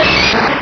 Cri_0066_DP.ogg